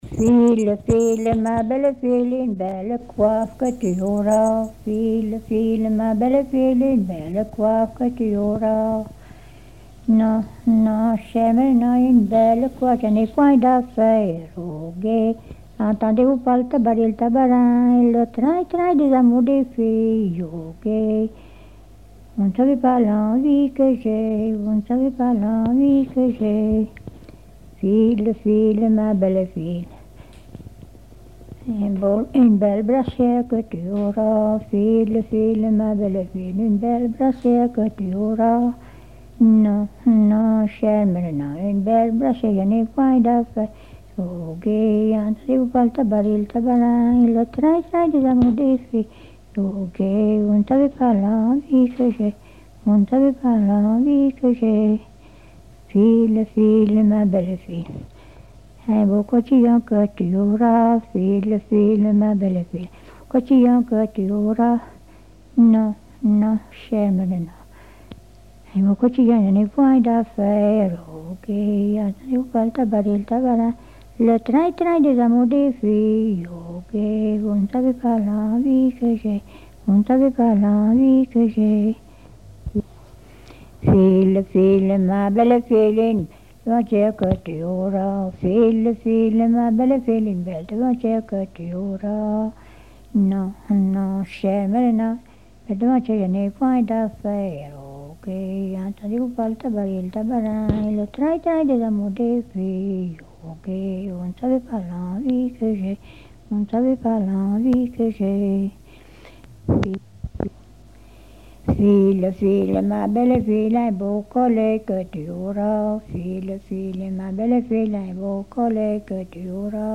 Genre énumérative
Chansons traditionnelles
Pièce musicale inédite